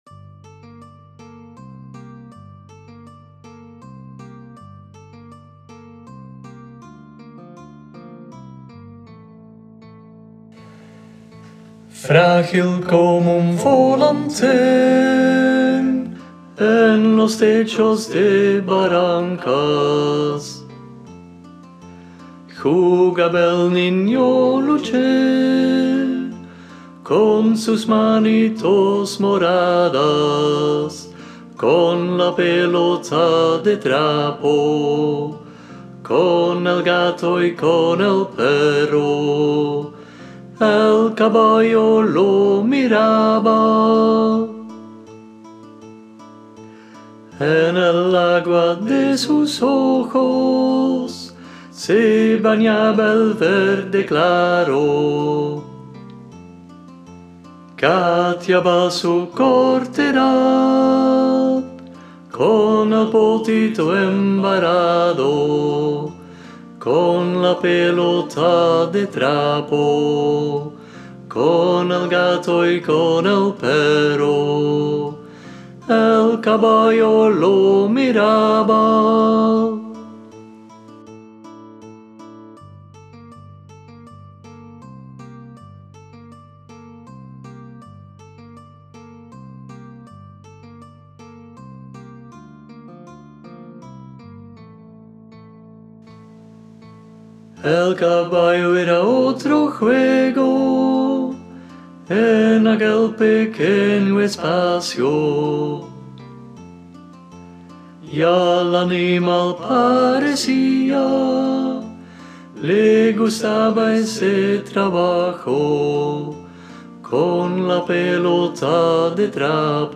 Luchín Sop.m4a